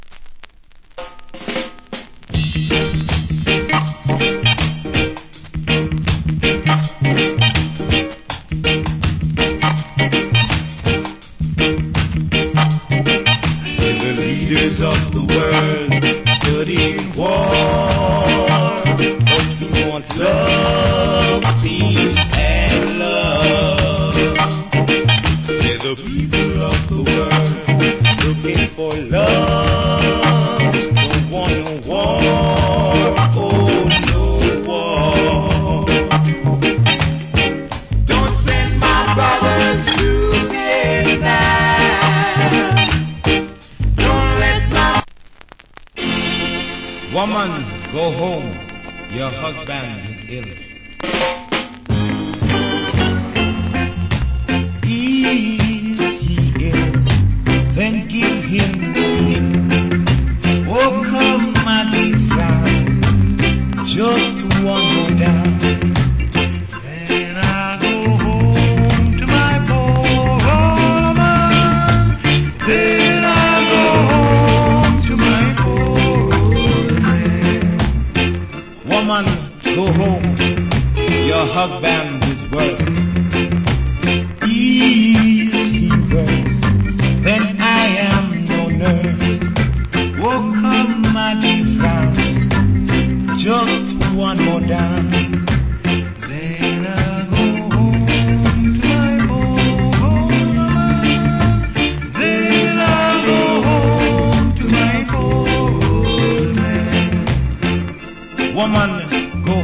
Rock Steady Vocal.